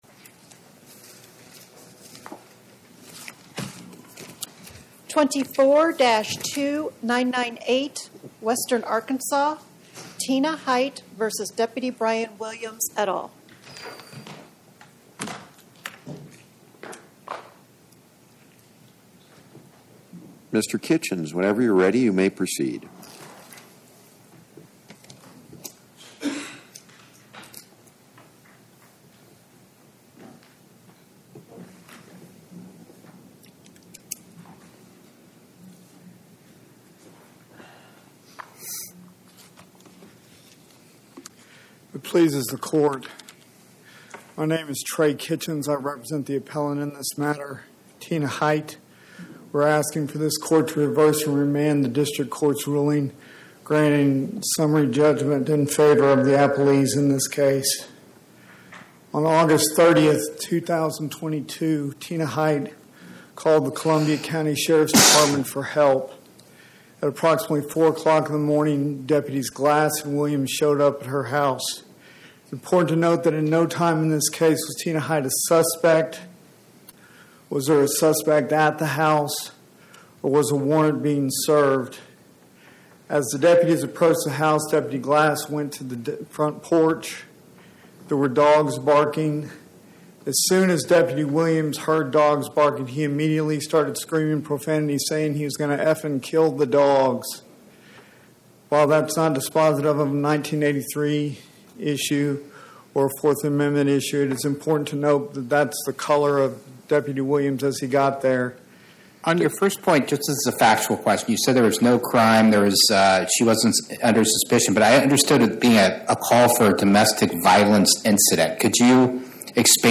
Oral argument argued before the Eighth Circuit U.S. Court of Appeals on or about 09/18/2025